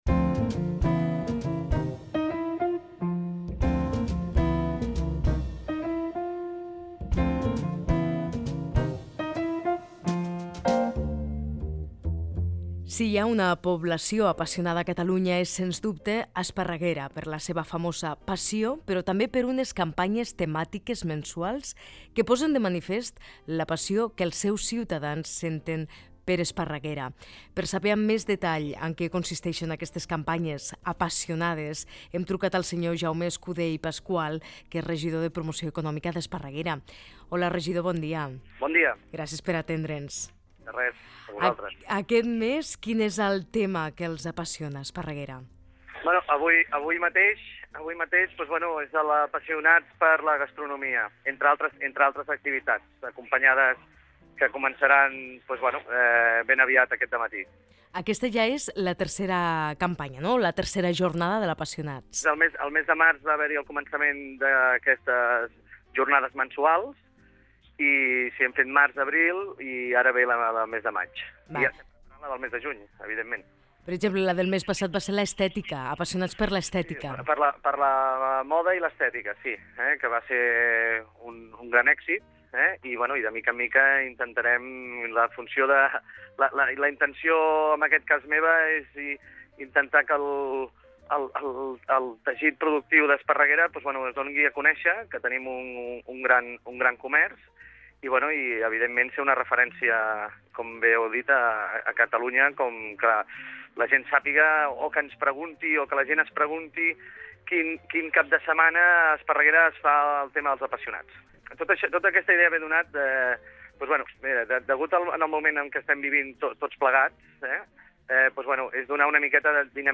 Fragment d'una entrevista a Jaume Escudé i Pasqual regidor d'Esparreguera, sobre el cicle d'events "Apassionats".